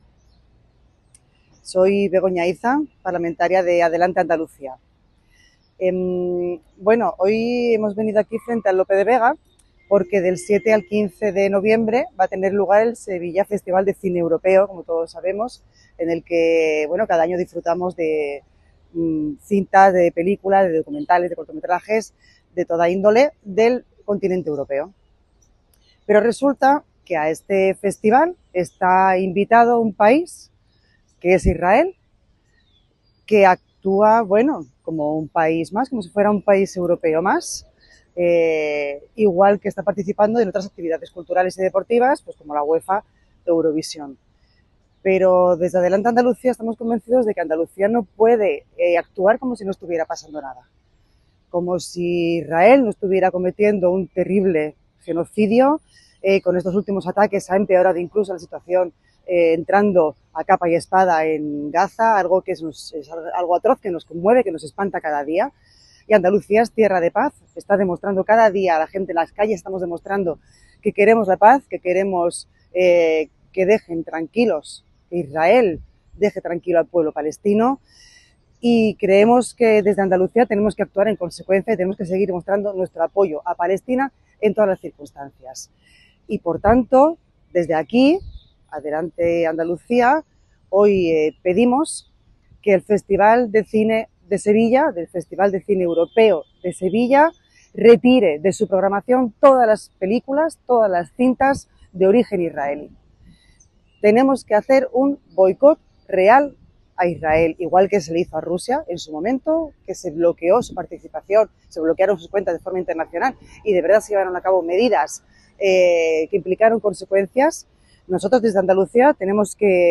Ha sido Begoña Iza, diputada en el Parlamento andaluz por Sevilla, la que ha señalado que cada año Israel actúa como un país invitado más como a otro tipo de eventos europeos y ha considerado imprescindible tomar medidas en el asunto ante el genocidio: “no se puede actuar como si no pasara nada, como si Israel no estuviese cometiendo un genocidio, Andalucía es tierra de paz y queremos actuar en consecuencia por lo que pedimos que el Festival retire las películas y documentales de origen israelí”
corte-begona-iza-festival-cine.mp3